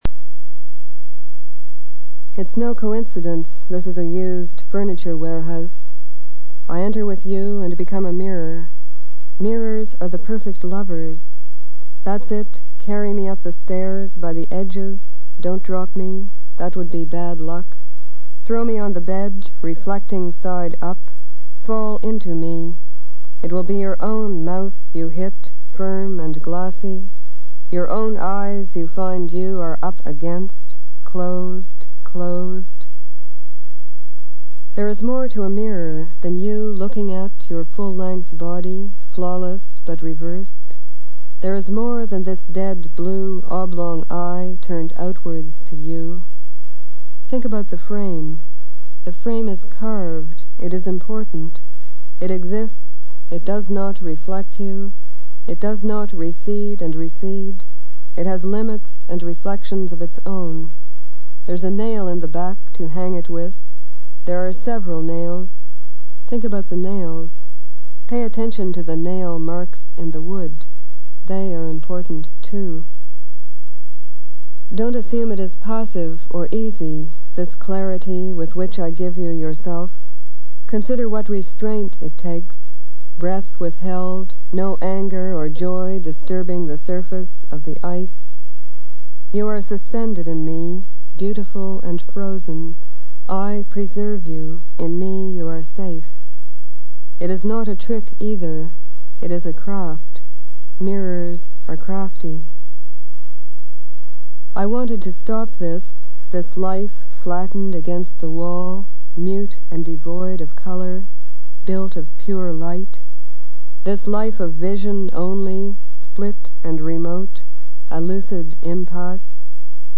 • Atwood: "Tricks with Mirror"(reading)